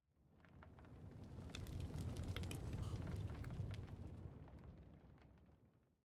Minecraft Version Minecraft Version 1.21.5 Latest Release | Latest Snapshot 1.21.5 / assets / minecraft / sounds / block / smoker / smoker3.ogg Compare With Compare With Latest Release | Latest Snapshot
smoker3.ogg